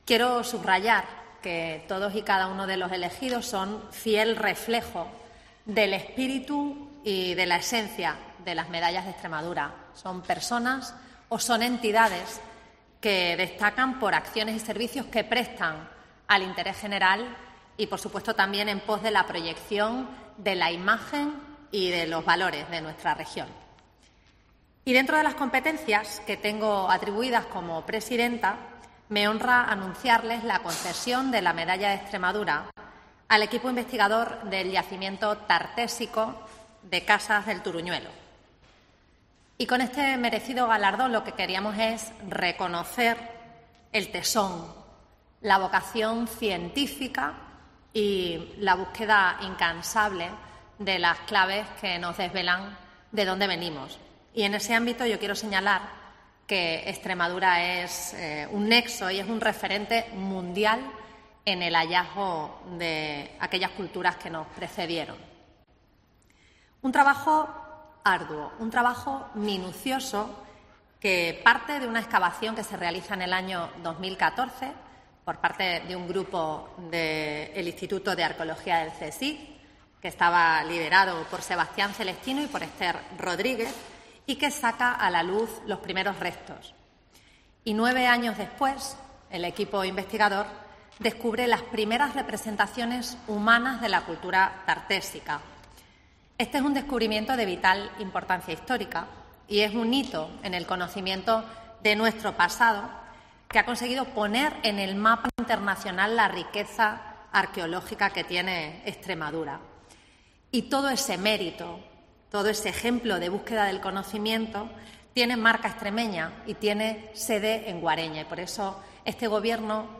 La presidenta de la Junta de Extremadura, María Guardiola, ha confirmado en rueda de prensa a los galardonados